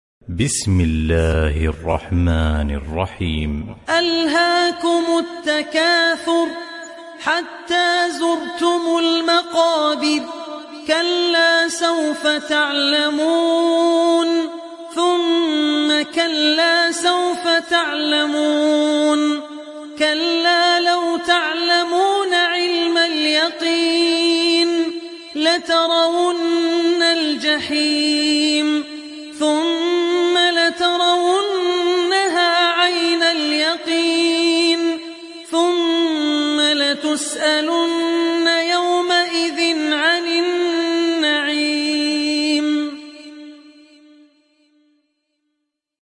تحميل سورة التكاثر mp3 بصوت عبد الرحمن العوسي برواية حفص عن عاصم, تحميل استماع القرآن الكريم على الجوال mp3 كاملا بروابط مباشرة وسريعة